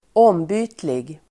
Uttal: [²'åm:by:tlig]